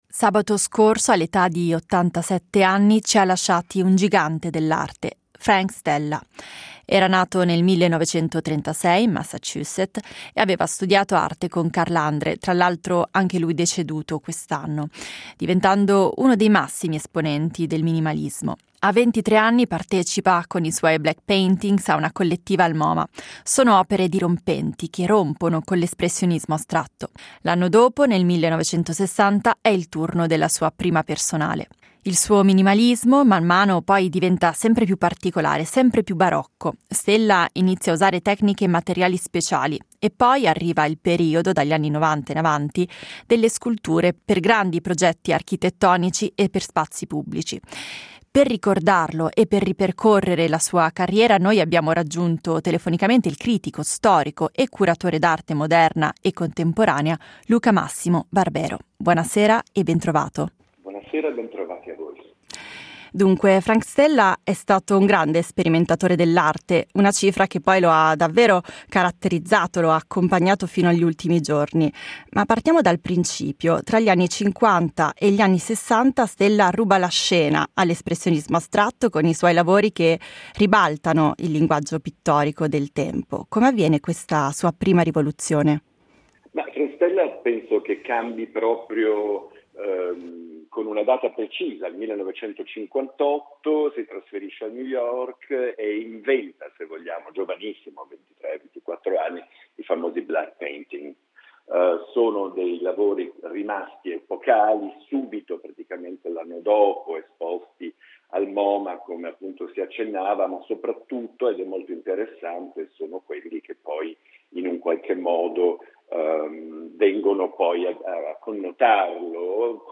Sperimenterà fino alla fine dei suoi giorni. Lo ha ricordato per noi il critico, storico e curatore d’arte moderna e contemporanea